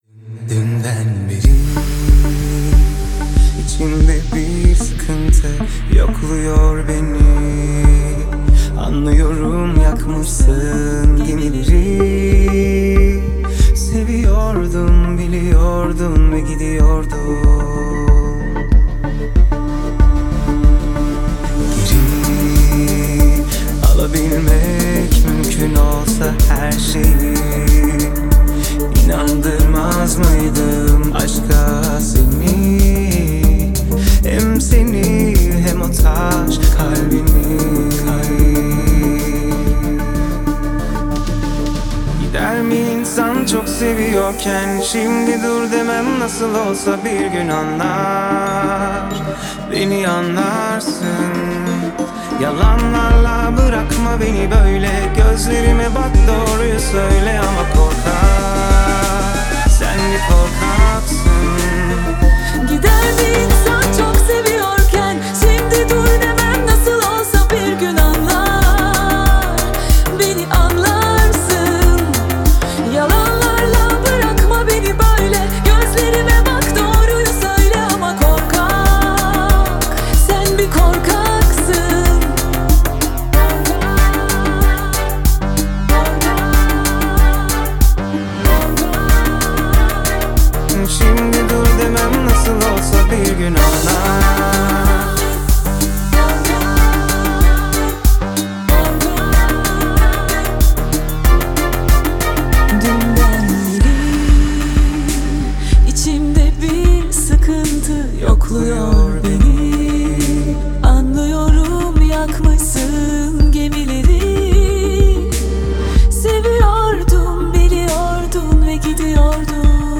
دانلود ریمیکس آهنگ